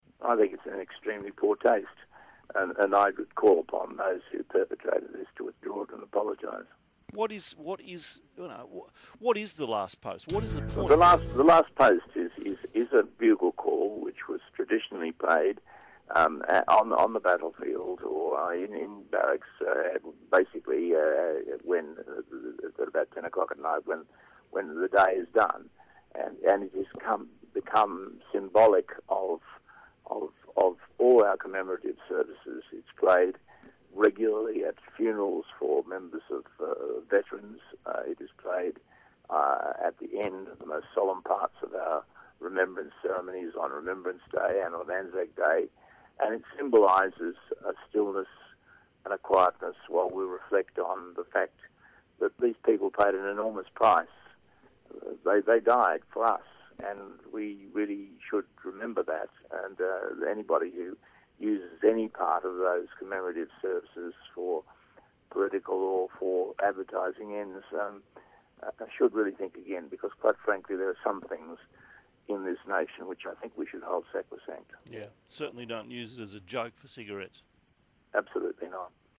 Rear Admiral Ken Doolan speaks to Neil Mitchell on 3AW